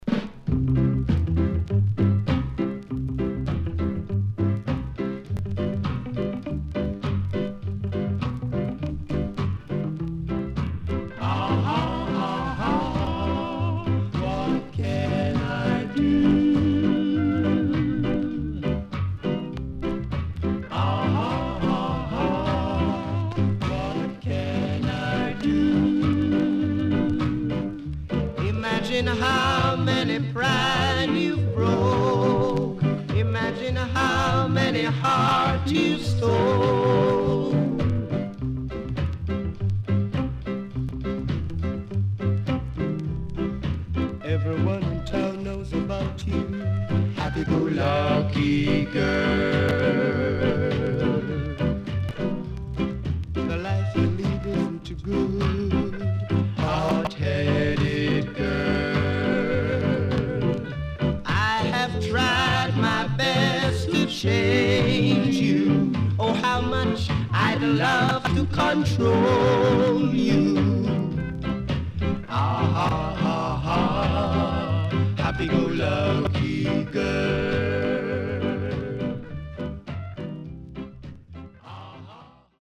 SIDE B:所々チリノイズ、プチノイズ入ります。